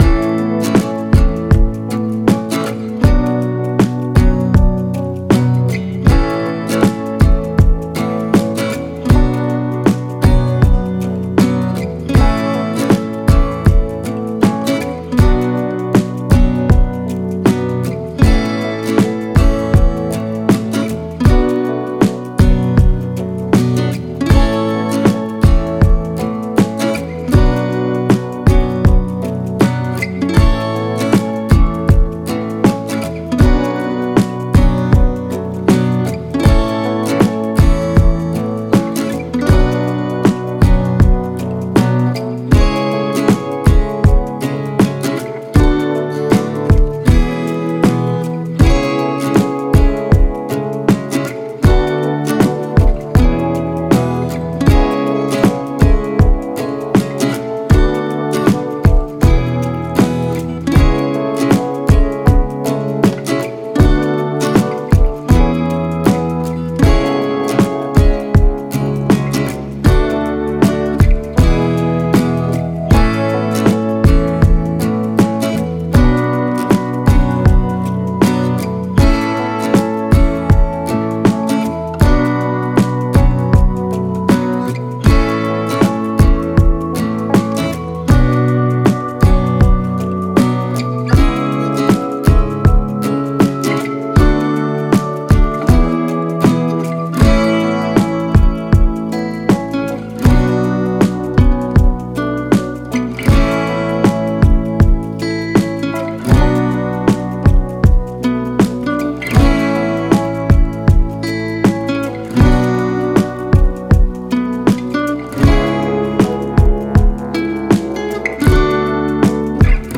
Genres: Country and local